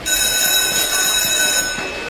• SCHOOL BELL BIG.wav
SCHOOL_BELL_BIG_lYK.wav